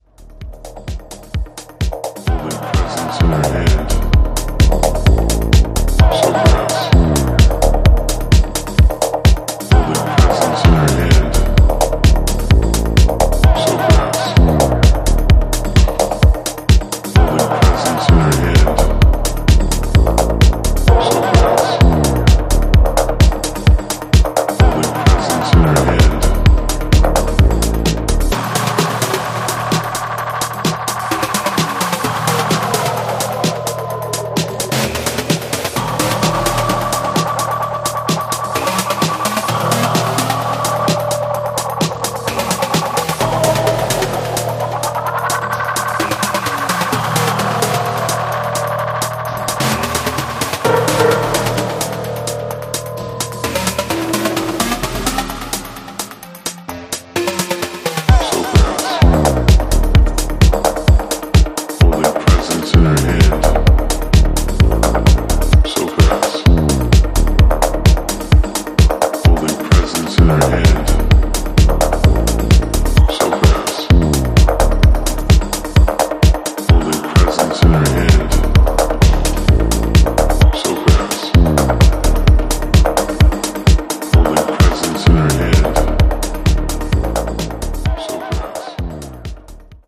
本作では、EBMやアシッド等の要素を盛り込んだダークでソリッドなエレクトロ・ハウスを展開しています。